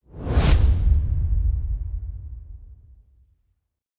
Simple Swoosh Sound Effect Free Download
Simple Swoosh